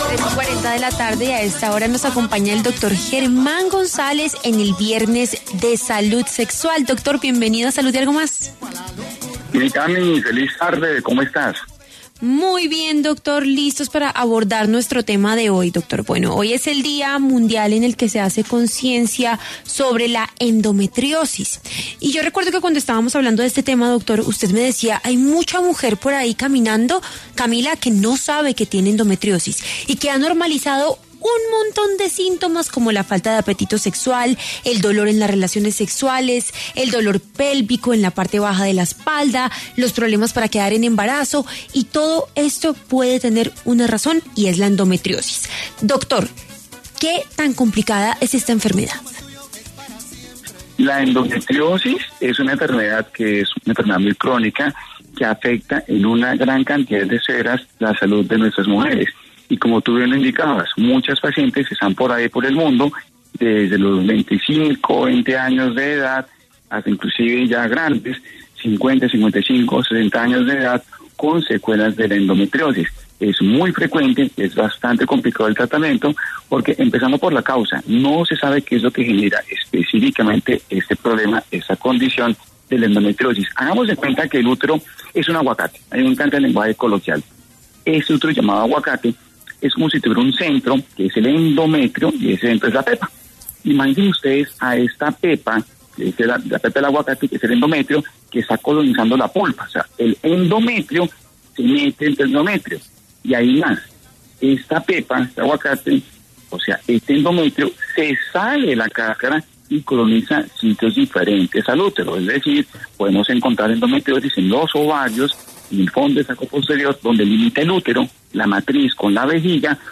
Según el especialista, aún no se conoce la causa de esta enfermedad que puede afectar a mujeres desde los 25 años en adelante.